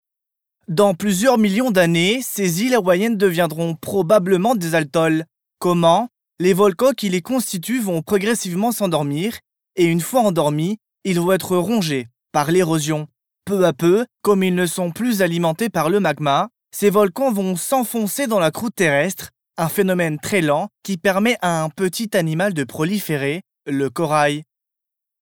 Voix OFF MASTER CHEF